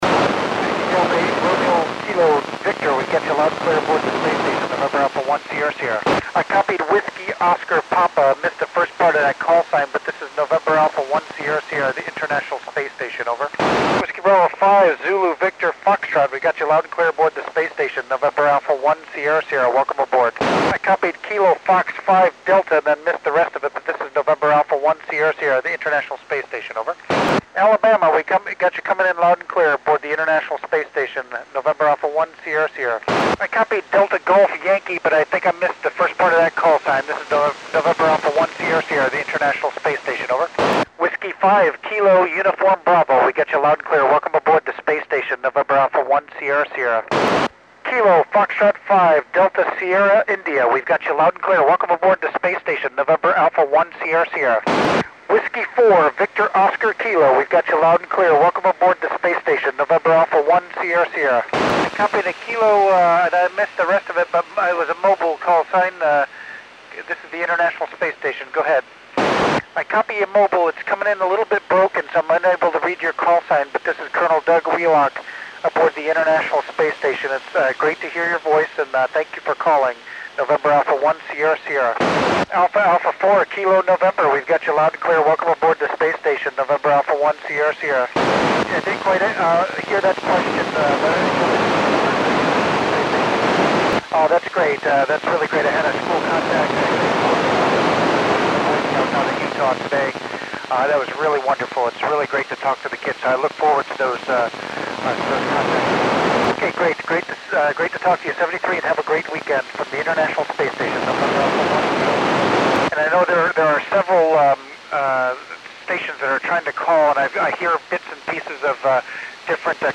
Col. Doug Wheelock (NA1SS) works U.S. stations on 18 September 2010 at 2342 UTC.
This was a 12 degree elevation pass for me, so quality suffers at the beginning and end of the recording (sorry about that).